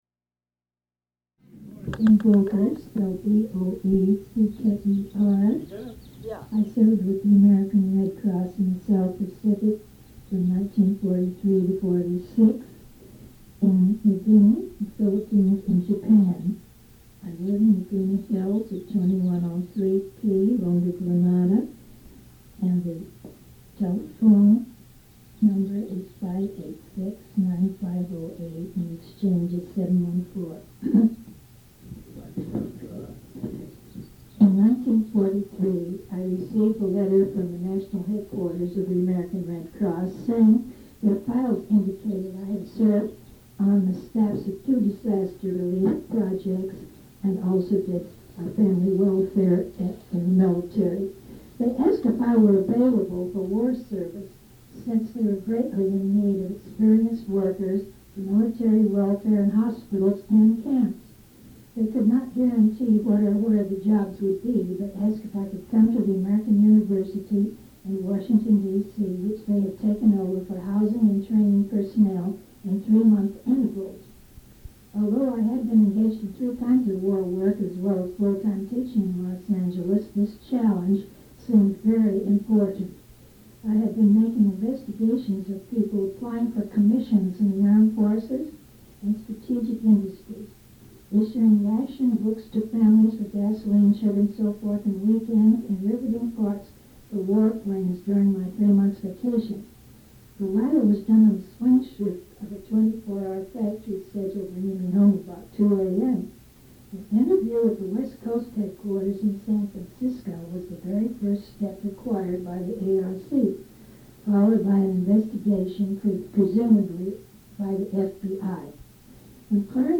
Sound quality deteriorates near the conclusion of the recording.
Interviews